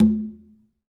Quinto-HitN_v3_rr2_Sum.wav